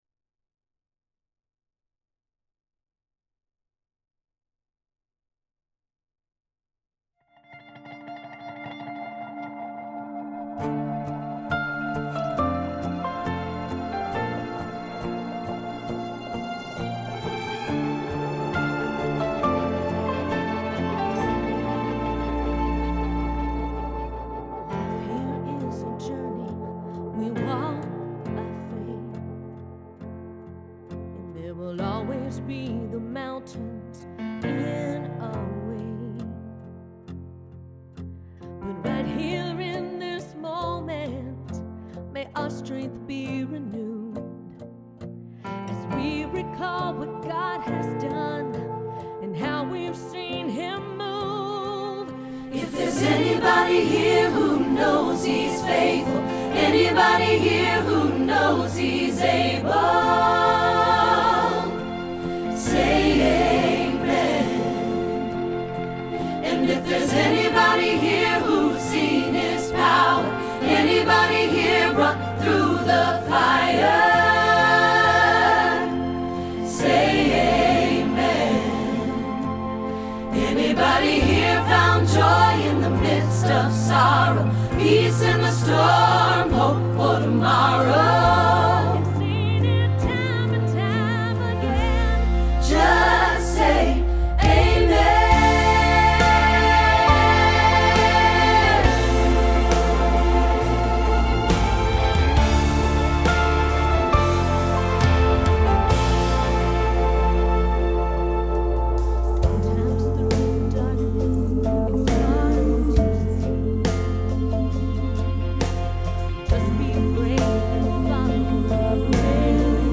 Say Amen – Alto – Hilltop Choir
07-Say-Amen-alto.mp3